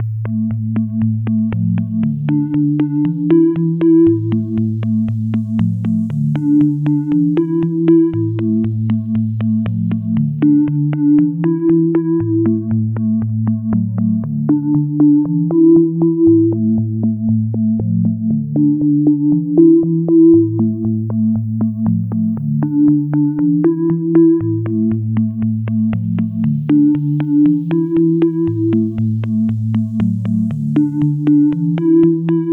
• Essentials Key Lead 4 118 bpm.wav